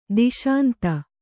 ♪ diśanta